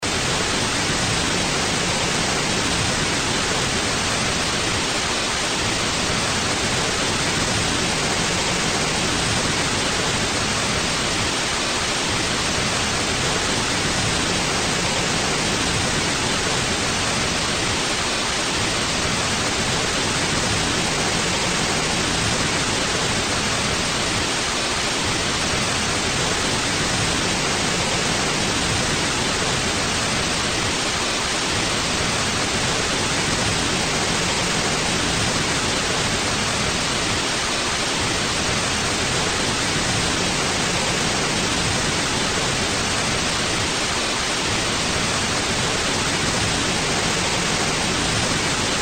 Bruit Blanc
02.-white_noise.mp3